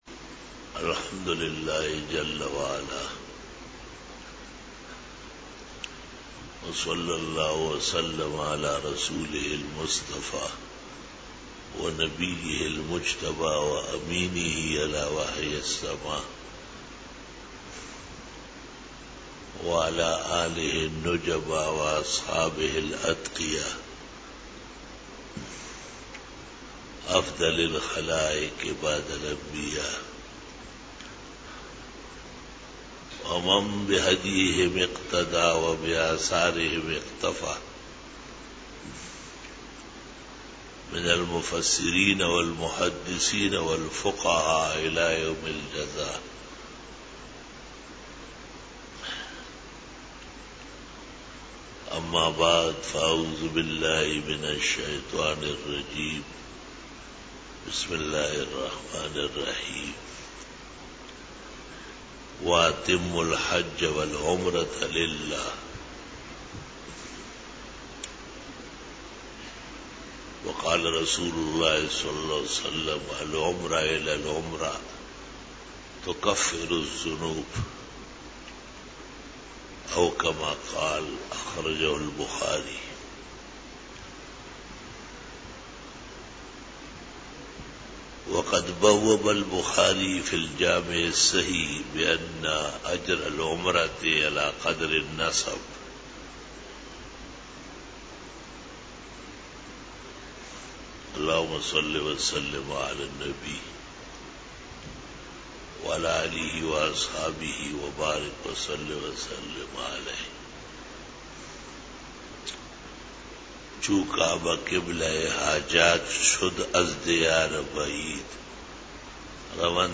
42 BAYAN E JUMA TUL MUBARAK (19 October 2018) (09 Safar 1440H)
Khitab-e-Jummah 2018